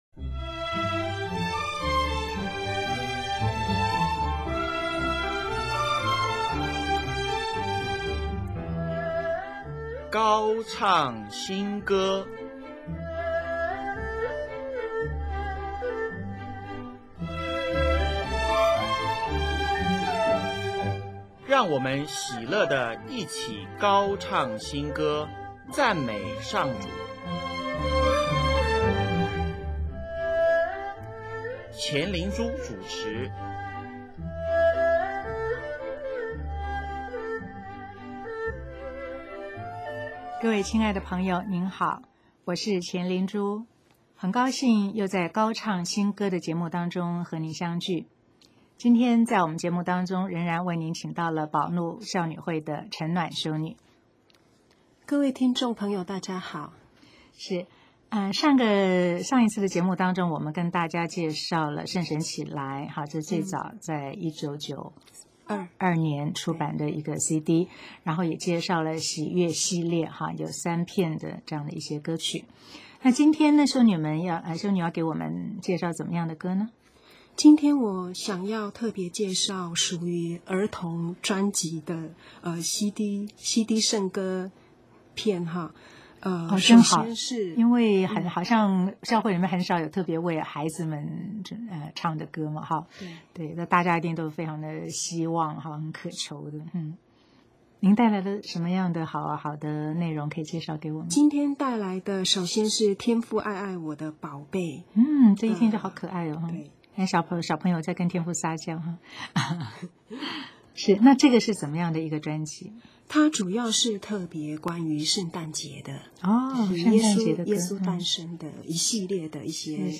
【高唱新歌】26|专访保禄孝女会(二)：小朋友圣乐系列
本集播放“天主经”、“圣母经”、“圣三光荣经”、“饭前经”。